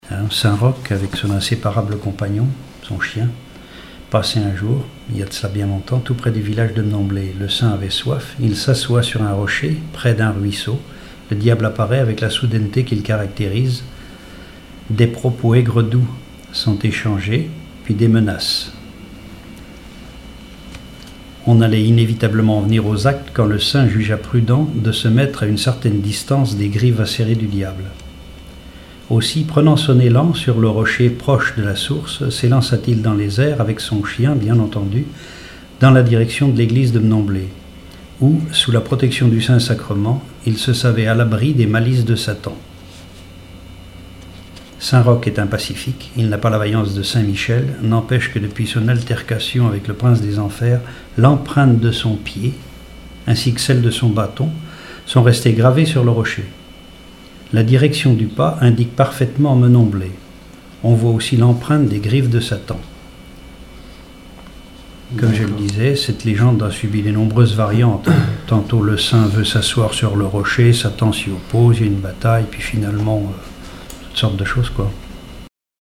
Genre légende
Témoignages ethnologiques et historiques
Catégorie Récit